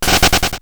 running.wav